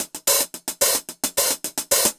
Index of /musicradar/ultimate-hihat-samples/110bpm
UHH_AcoustiHatB_110-02.wav